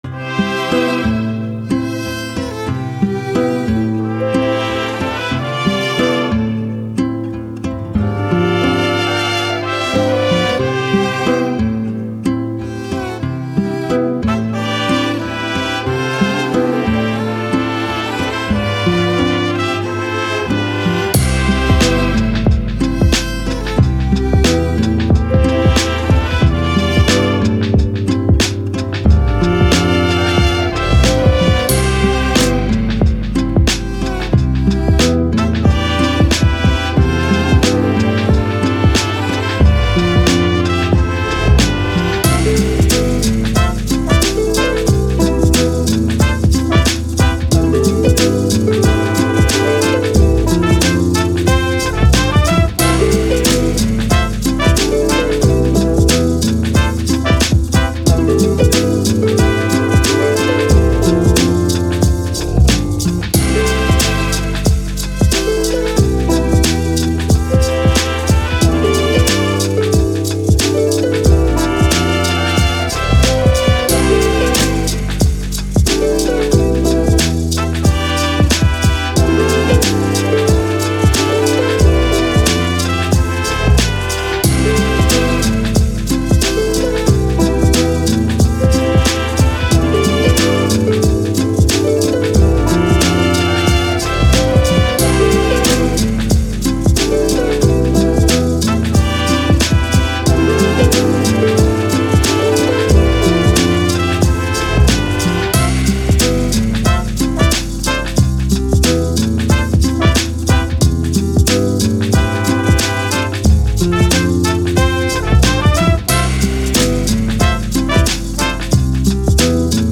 Hip Hop, Vintage, Playful, Brass